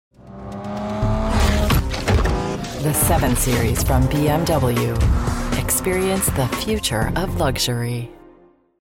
BMW_Luxury_Sultry
Neutral, Mid-Atlantic
Middle Aged